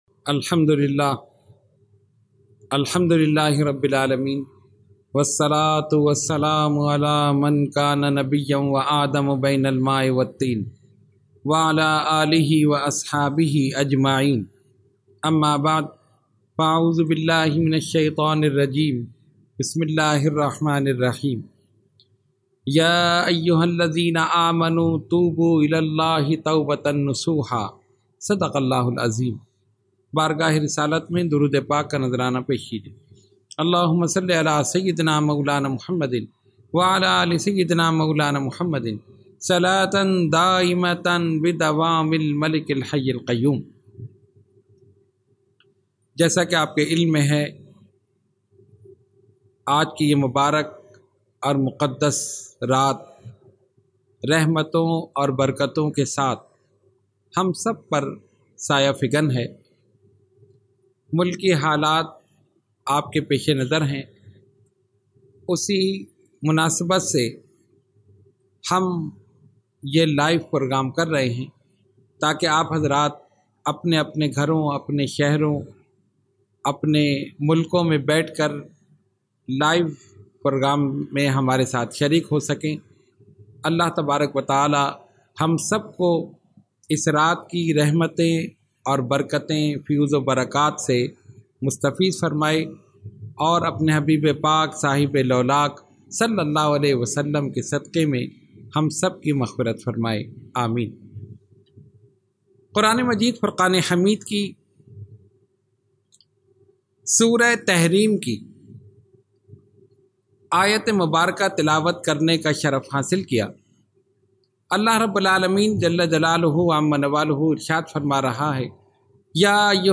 Category : Speech | Language : UrduEvent : Shab e Baraat 2020